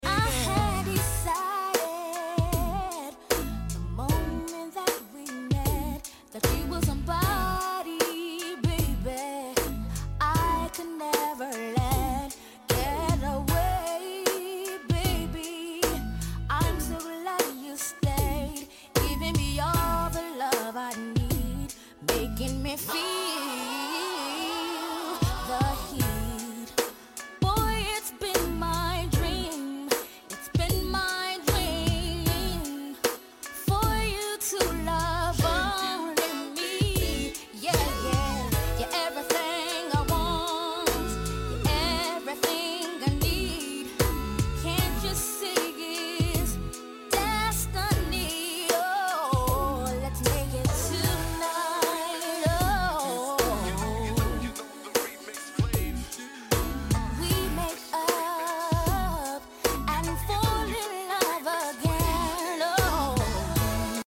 #90srnb